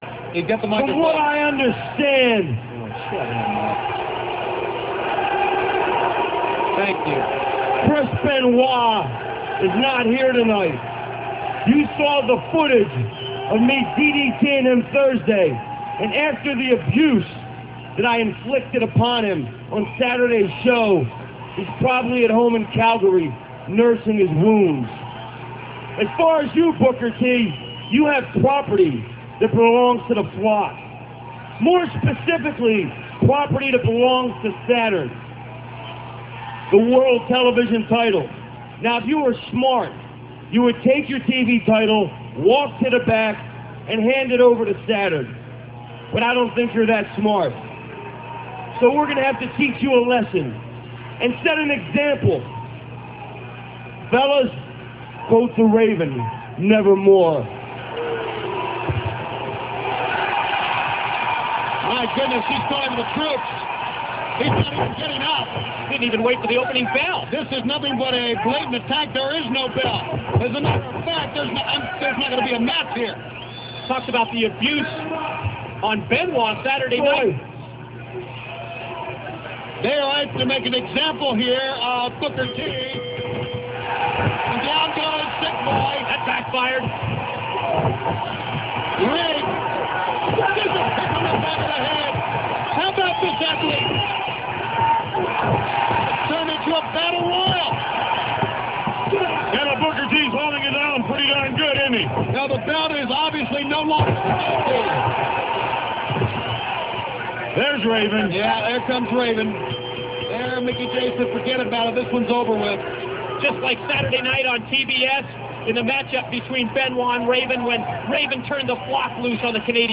- This speech comes from WCW Monday Nitro - [2.9.98]. Raven talks about how Chris Benoit was beaten too badly to come to Nitro after a match with Raven on WCW Saturday Night.